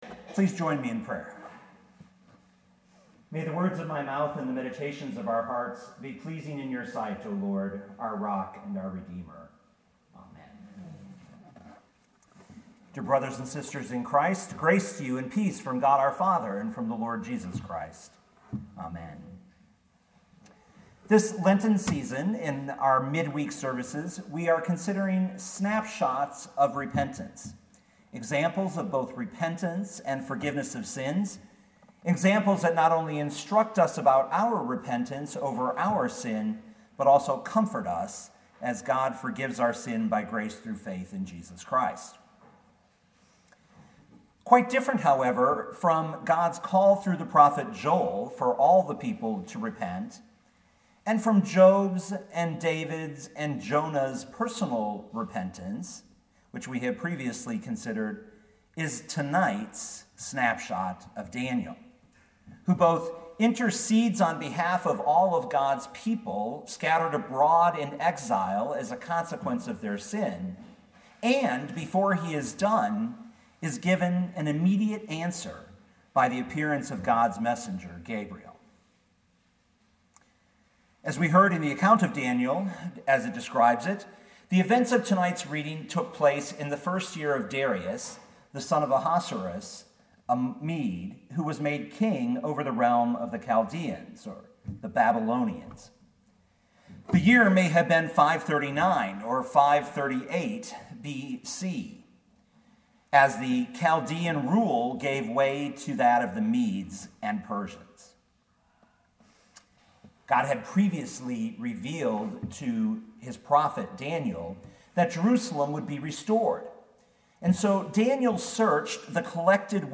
2018 Daniel 9:1-23 Listen to the sermon with the player below